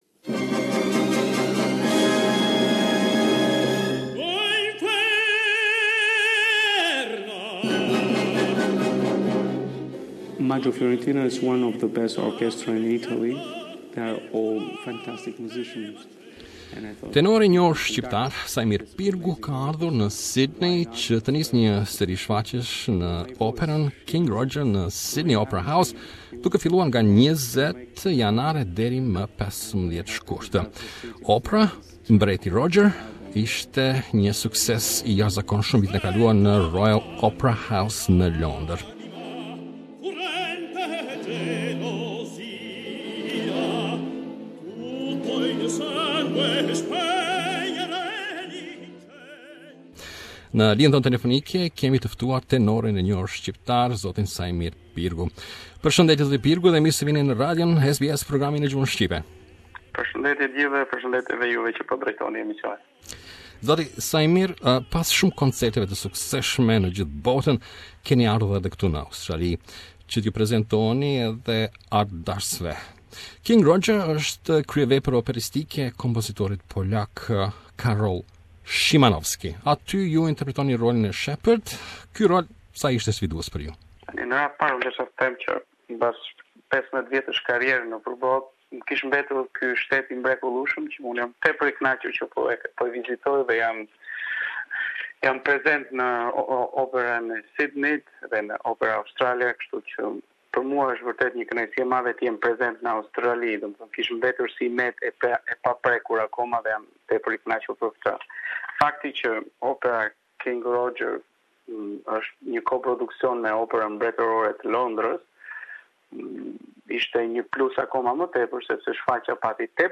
Interview: Young Albanian tenor Saimir Pirgu as Shepherd in Opera Australia's production of King Roger